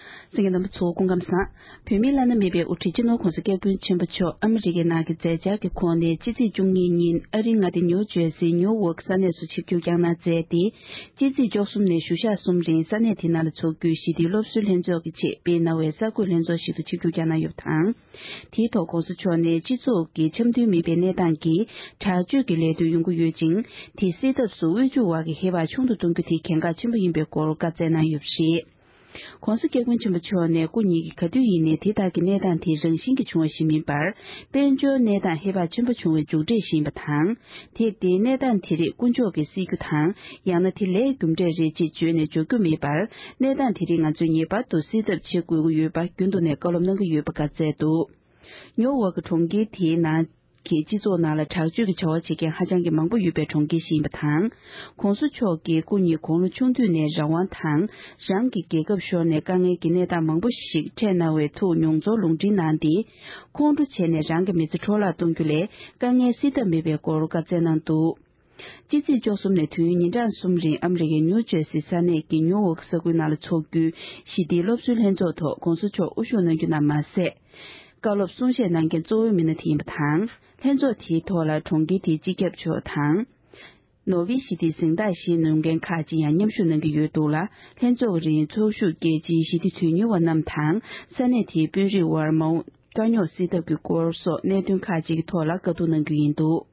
སྤྱི་ཚོཊ་ནང་དྲག་སྤྱོད་ལས་དོན་སེལ་ཐབས། ཕྱི་ཚེས་བཅུ་གཉིས་ཀྱི་ཉིན་ཨ་རིའི་མངའ་སྡེ་ནིའུ་ཇར་སིའི་ནི་ལྦཀ་ས་གནས་སུ་༸གོང་ས་མཆོག་ནས་གསར་འགོད་ལྷན་ཚོགས།
སྒྲ་ལྡན་གསར་འགྱུར།